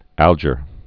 (ăljər), Horatio 1832-1899.